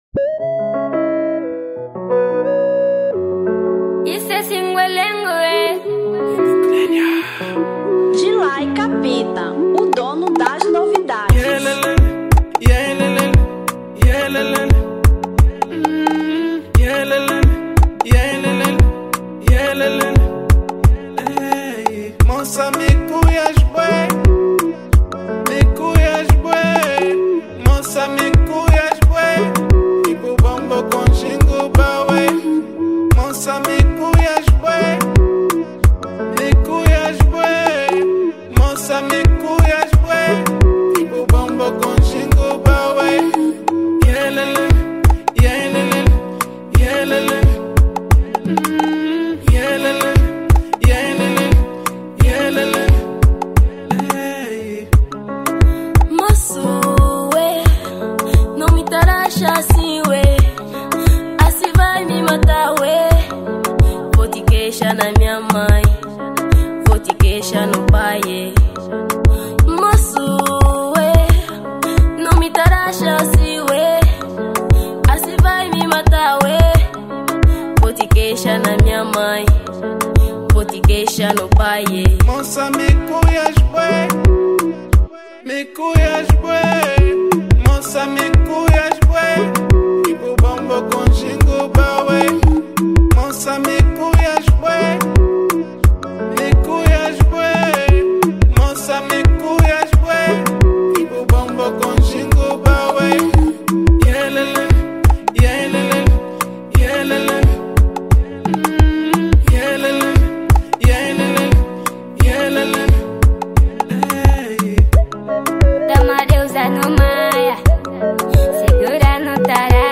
Tarraxinha 2025